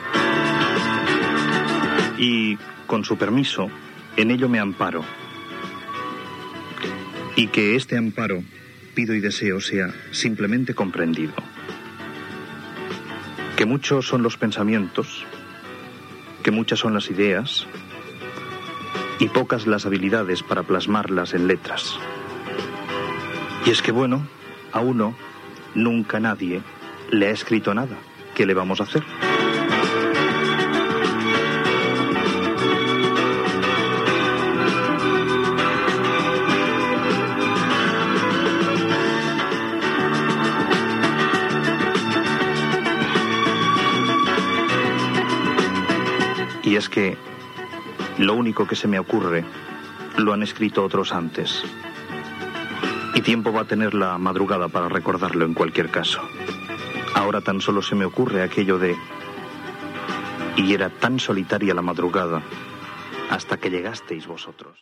Fragment del comiat de l'últim programa